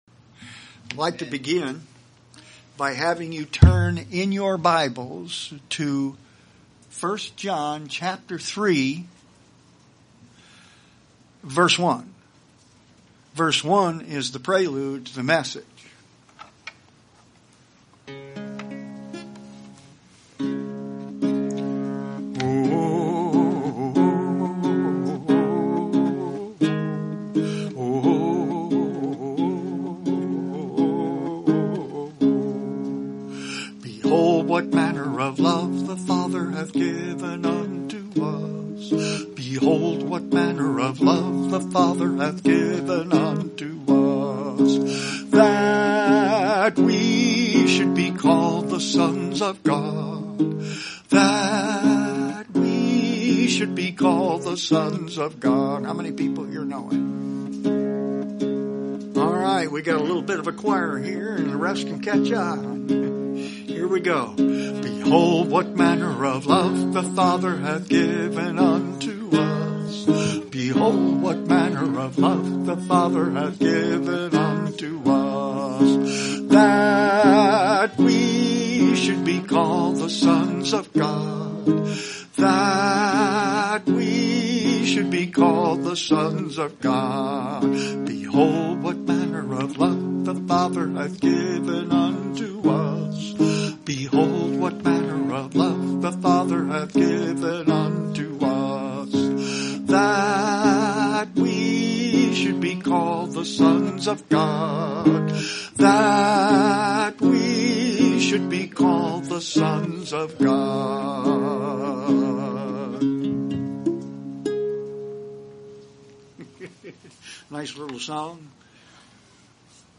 Visiting Pastor Sermons